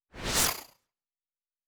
Synth Whoosh 4_5.wav